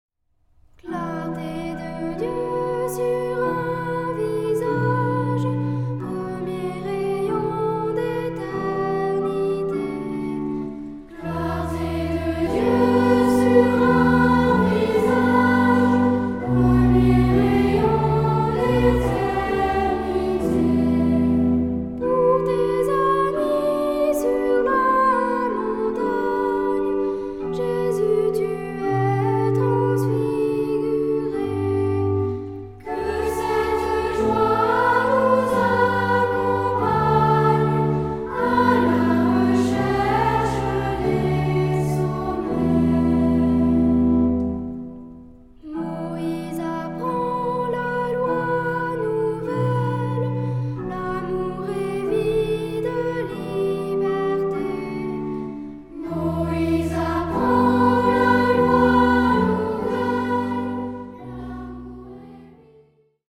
unisson + SATB (4 voix mixtes)
Cantique.
Genre-Style-Forme : Cantique ; Sacré
Orgue
Tonalité : mi mineur